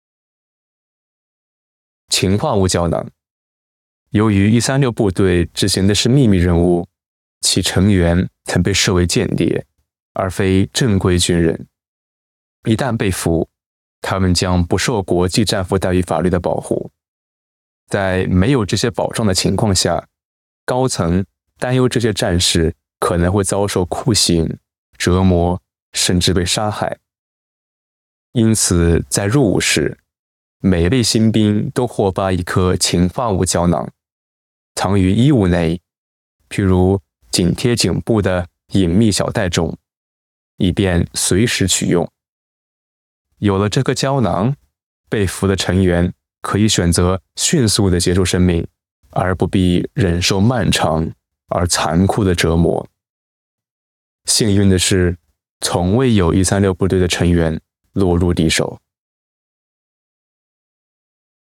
Special Operations Voiceovers